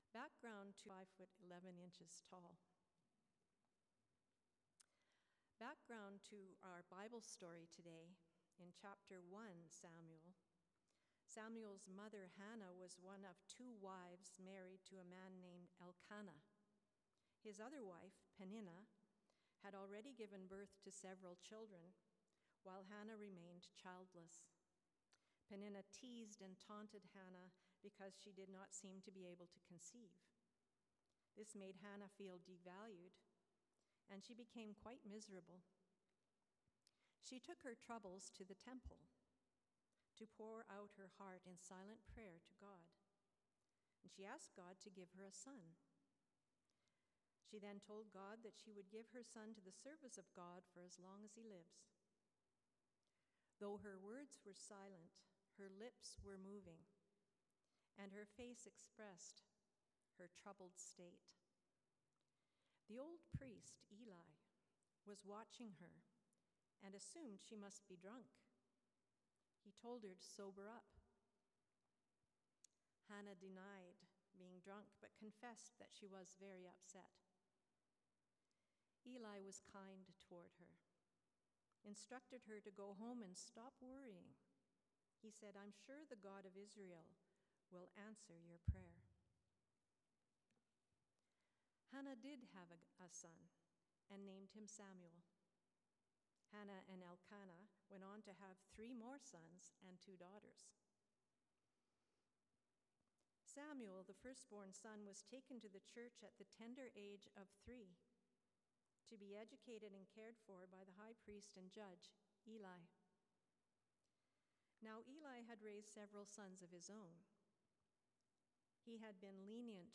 Sermons | Okotoks United Church